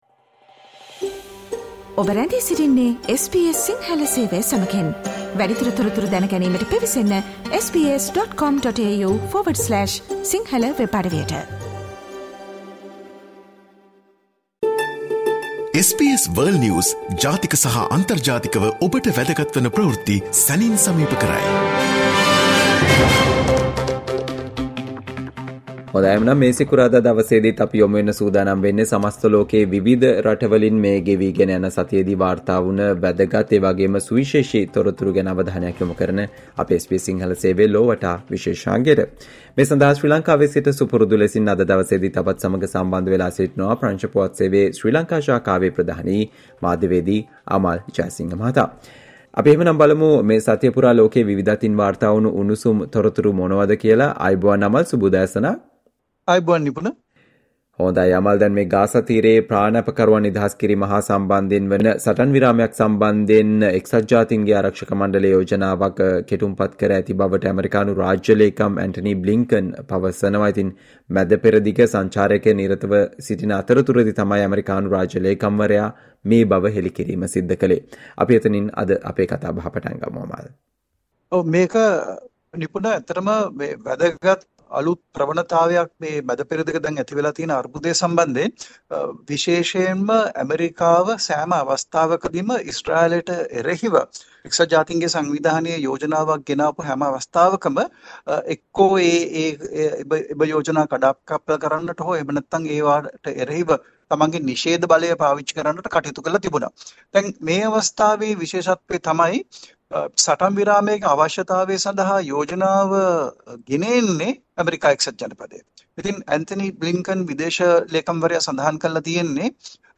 listen to the world’s most prominent news highlights.